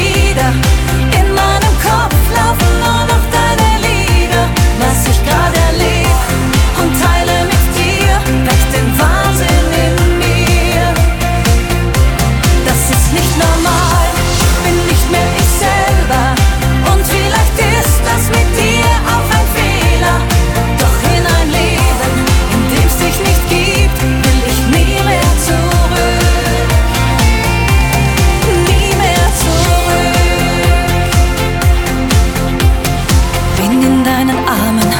Рингтоны
Жанр: Поп
# German Pop